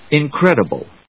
音節in・cred・i・ble 発音記号・読み方
/ìnkrédəbl(米国英語), ˌɪˈnkredʌbʌl(英国英語)/